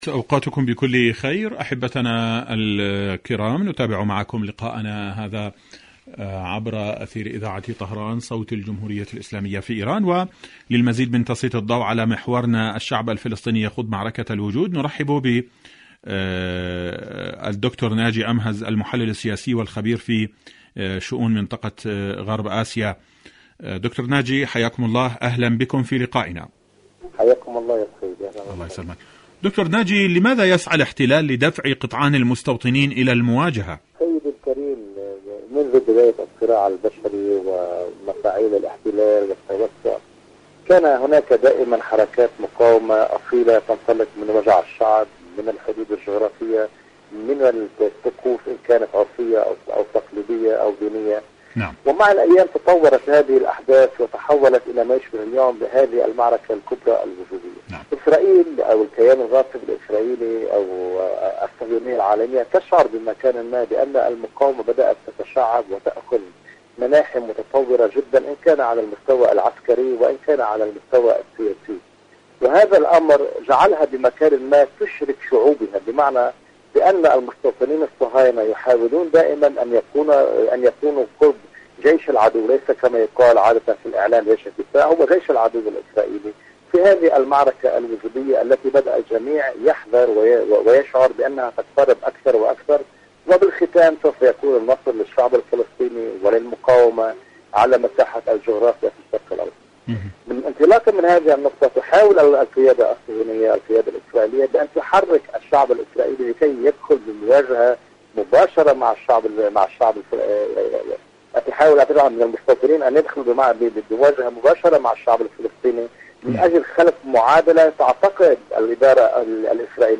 مقابلات برنامج ارض المقاومة المقاومة محور المقاومة مقابلات إذاعية الشعب الفلسطيني فلسطين المحتلة معركة الوجود برامج إذاعة طهران العربية شاركوا هذا الخبر مع أصدقائكم ذات صلة يوم القدس، يوم الأمة لفلسطين..